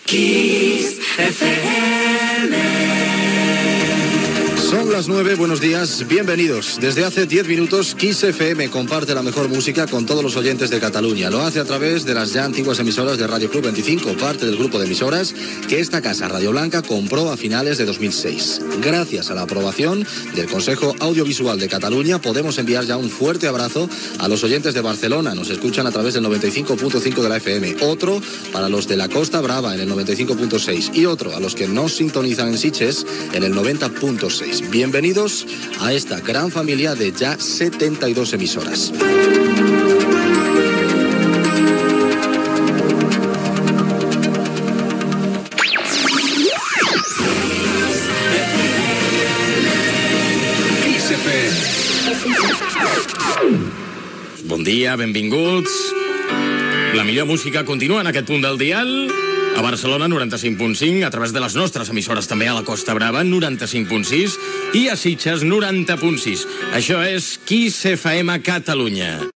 Indicatiu, hora i salutació a l'audiència de Catalunya i freqüències d'emissió en el primer dia que la ràdio disposava de freqüències legals a Barcelona, Sitges i Costa Brava.